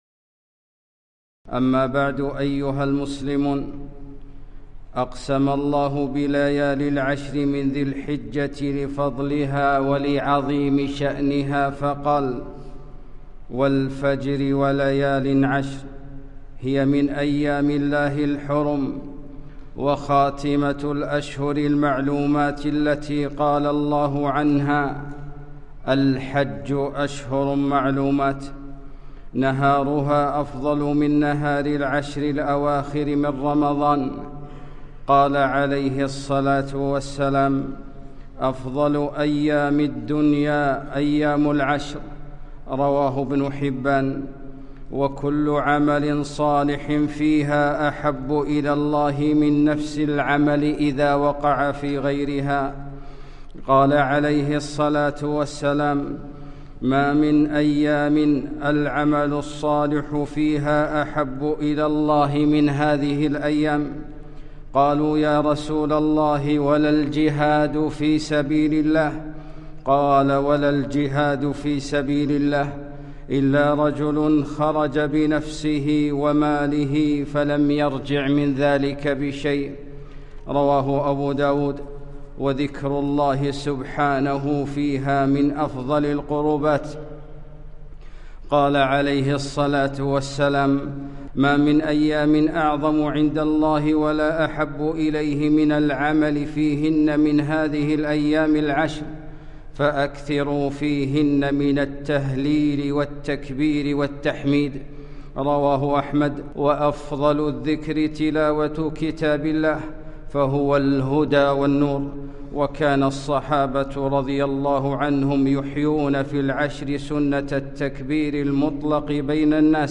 خطبة - فصل لربك وانحر